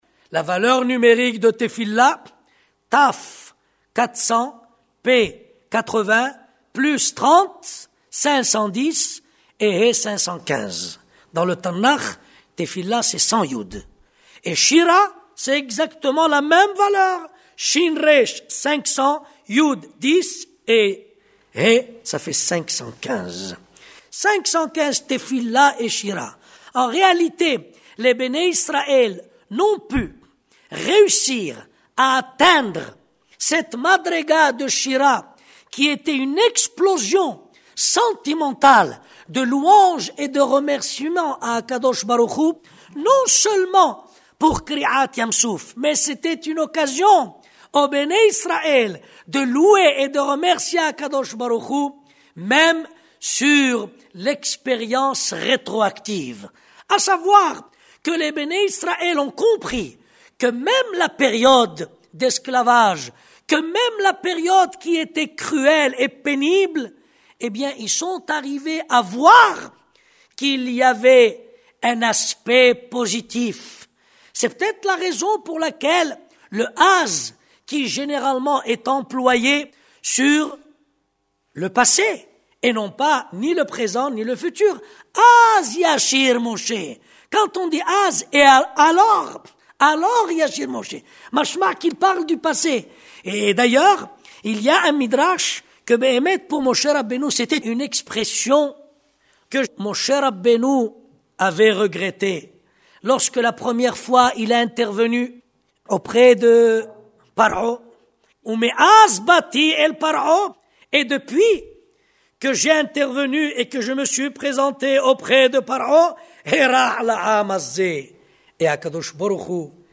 Son exposé a été donné également pour soutenir la Yeshiva d’Ofakim du Néguev.